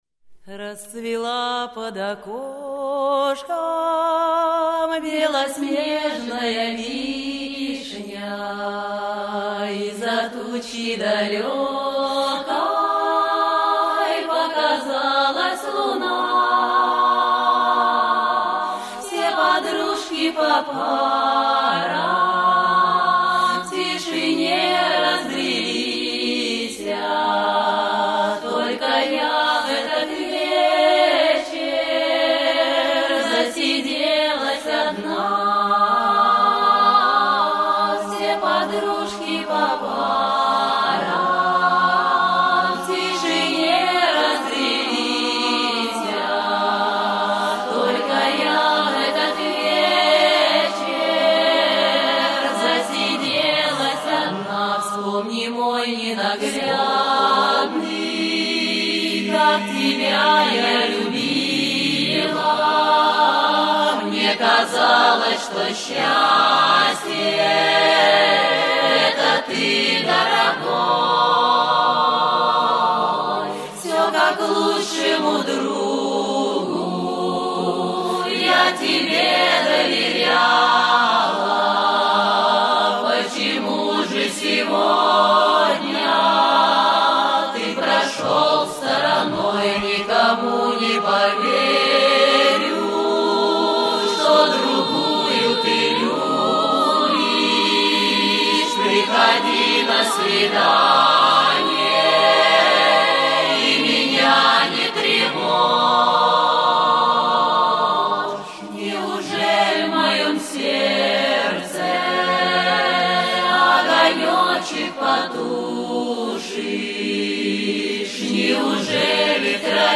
zastolnyie-pesni----russkaya-pesnya----rastsvela-pod-okoshkom-belosnejnaya-vishnya.mp3